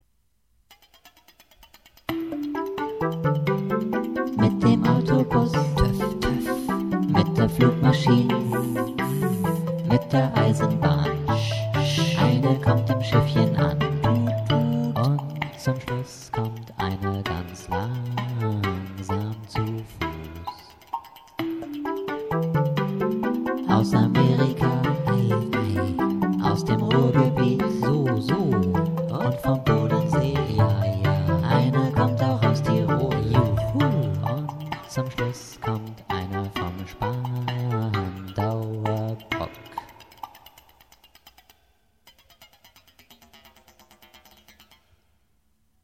chor
voice and programming